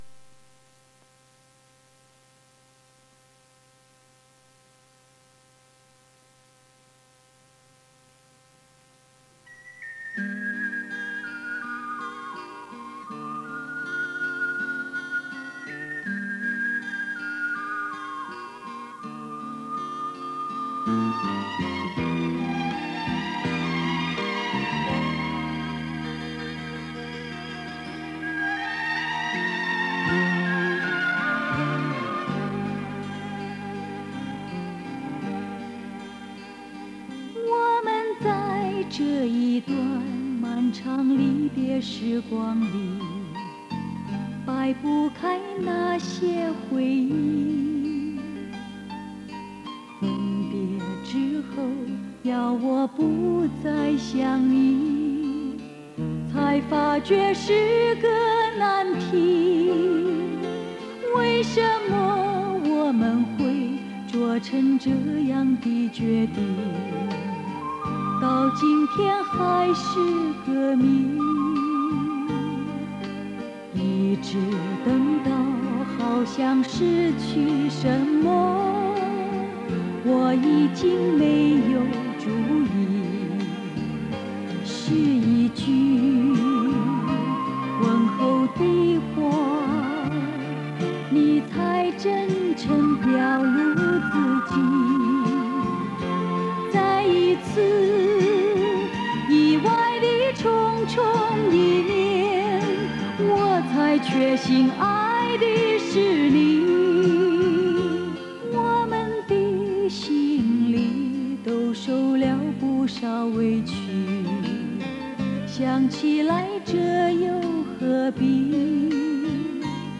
国语演唱：